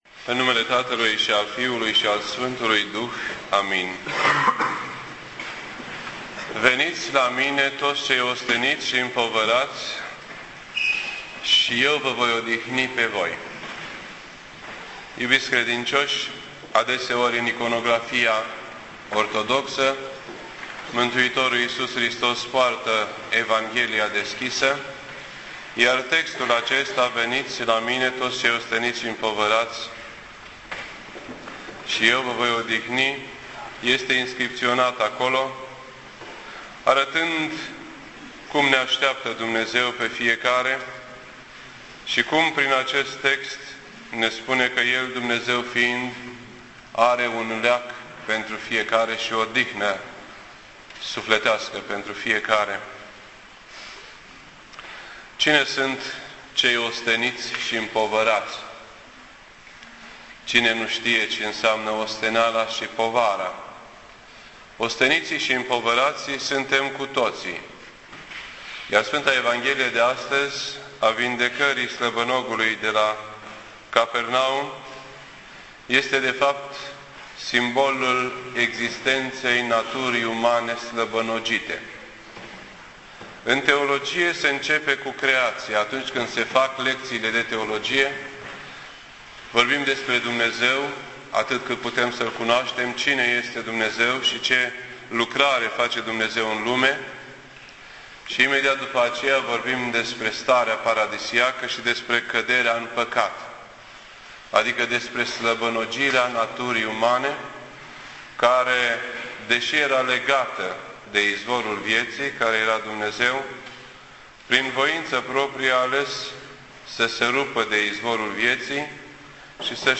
This entry was posted on Sunday, February 28th, 2010 at 8:45 PM and is filed under Predici ortodoxe in format audio.